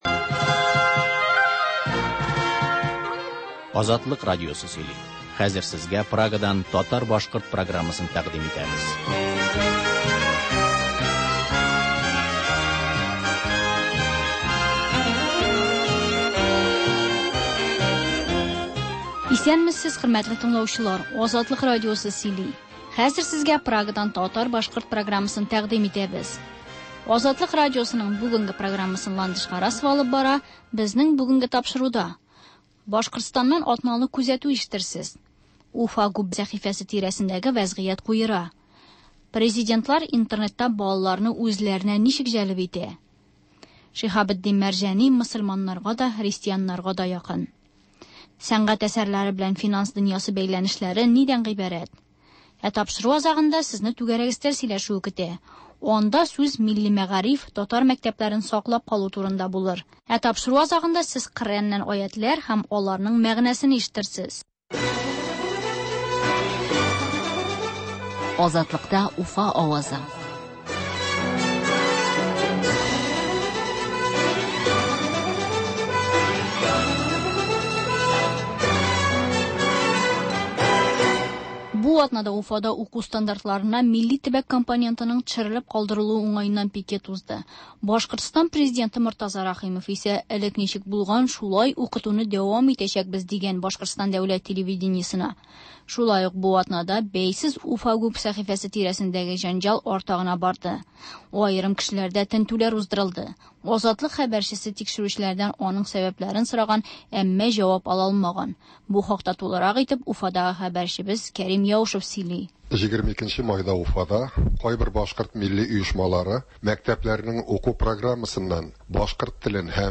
Азатлык радиосы бар атнага күз сала - Башкортстаннан атналык күзәтү - түгәрәк өстәл артында сөйләшү